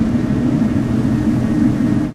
techage_turbine.ogg